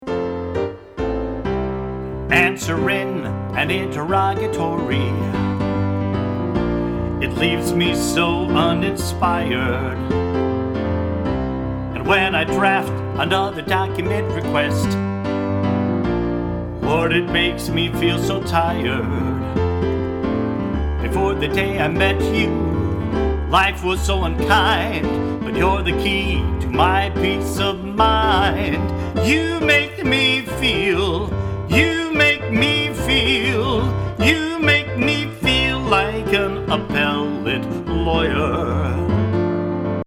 musical parodies